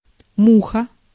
Ääntäminen
IPA: [muʃ]